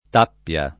[ t # pp L a ]